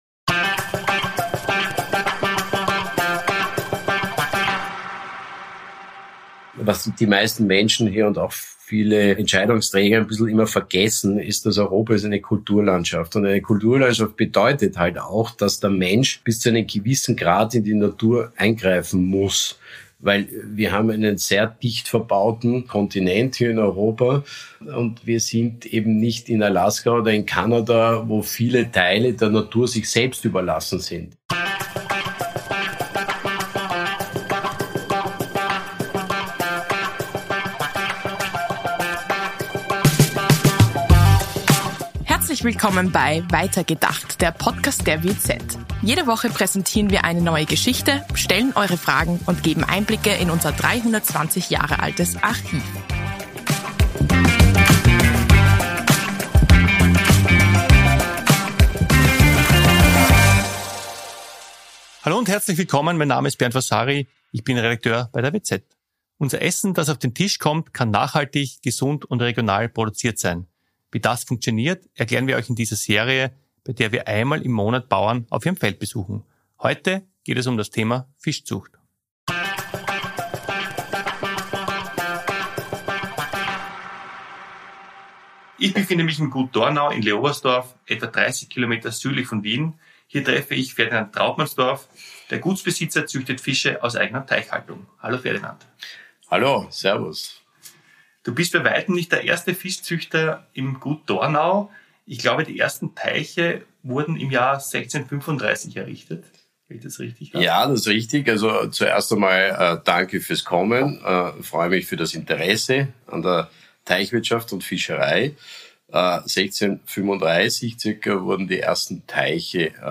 Beschreibung vor 2 Jahren Unser Essen kann gesund, nachhaltig und regional produziert sein. Wie das funktioniert, erklären wir euch in dieser Serie, bei der wir einmal im Monat Bauern vor Ort besuchen.